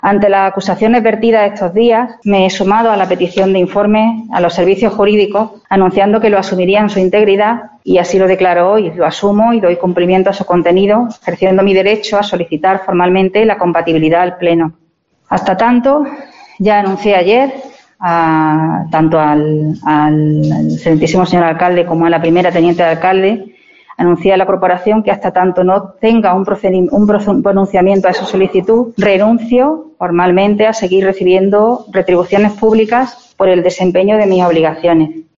En una rueda de prensa, la edil, que se ha mostrado afectada, ha explicado que "en la declaración de intereses y actividad previa al jurar el cargo como concejal" incluyó "expresamente" su actividad como procuradora, así como "en el apartado de causa de posible incompatibilidad", si bien se le "informó de manera verbal, extremo éste que ha sido ratificado en el informe de Secretaría de Pleno, que mientras no planteara procedimientos contra los intereses de esta Corporación no incurriría en incompatibilidad alguna".